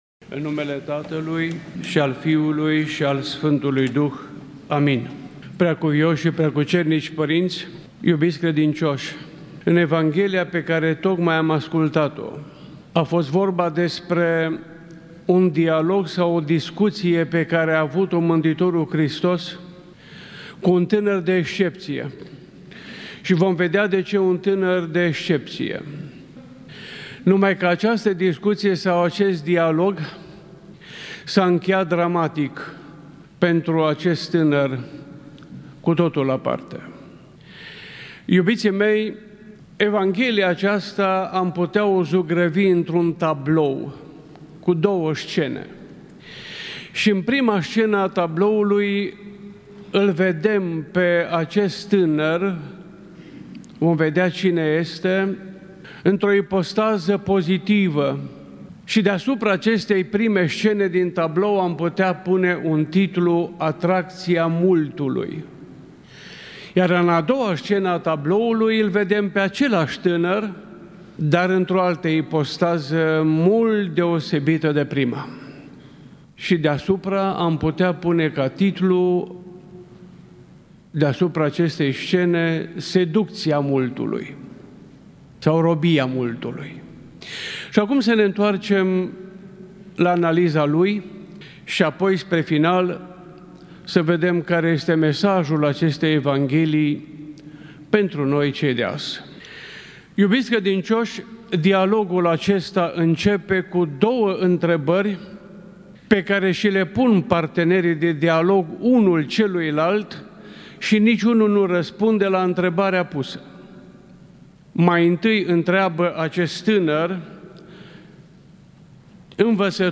Predică la Duminica a 30-a după Rusalii